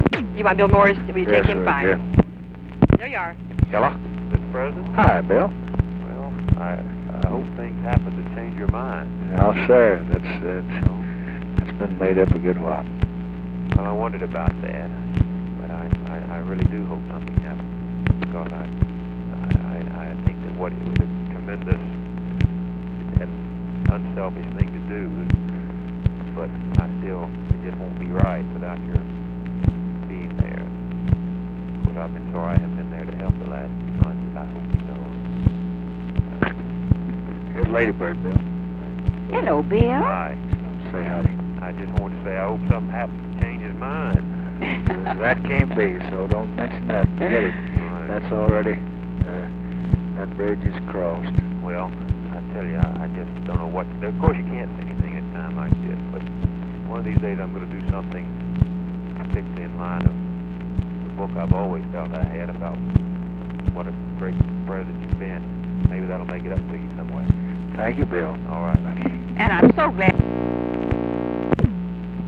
Conversation with BILL MOYERS and LADY BIRD JOHNSON, April 1, 1968
Secret White House Tapes